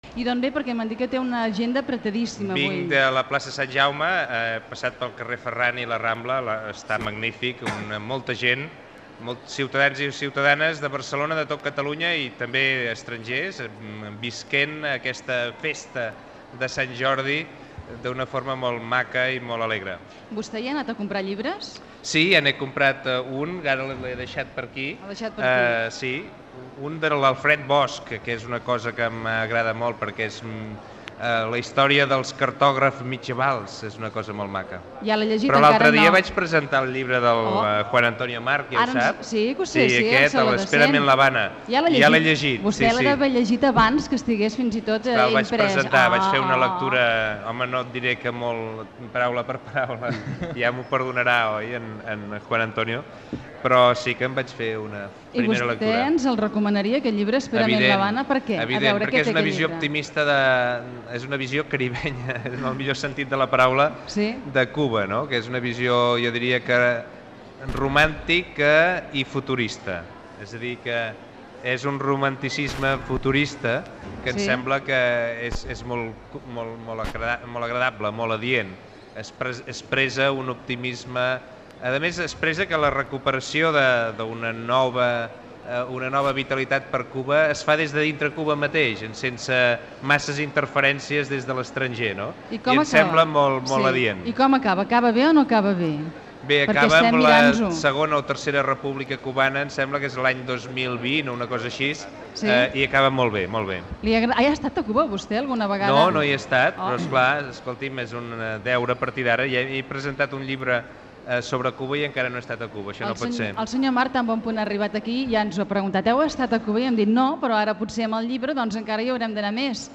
Fragment d'una entrevista a l'alcalde de Barcelona Joan Clos a l'especial Sant Jordi.
Entreteniment